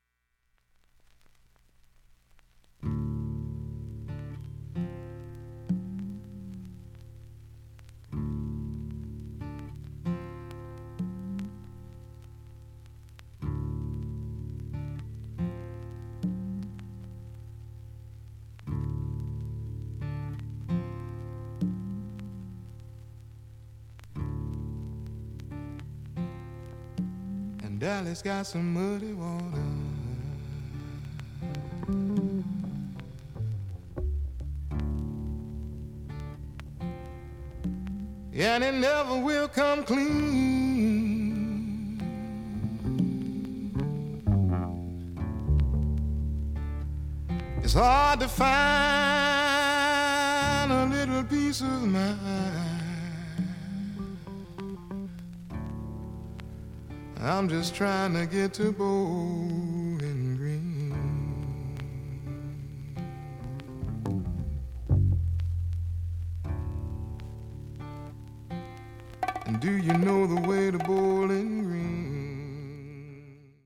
全体クリアないい音質です、
B-3序盤に４ミリ薄いスレで
６５秒の間に周回プツ出ますがかすかで、
聴き取れるか不安なレベルです。